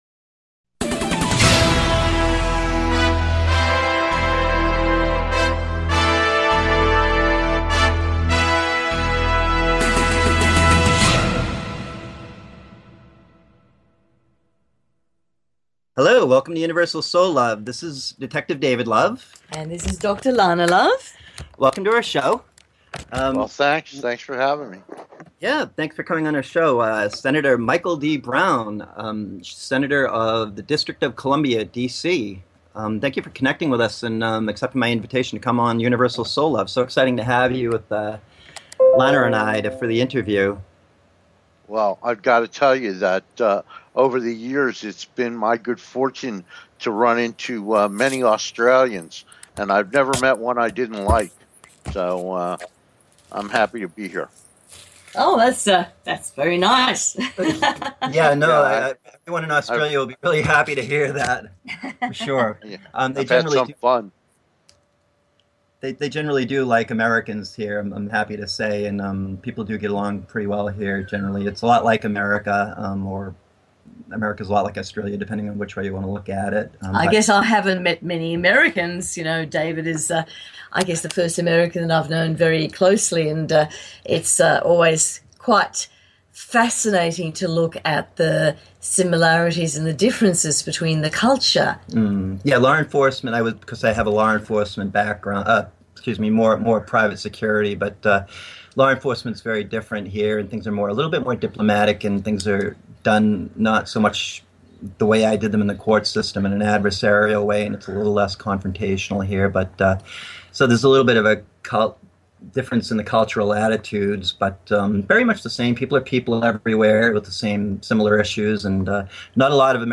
Guest, Michael Brown